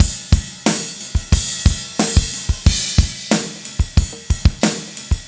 10 rhdrm91ride.wav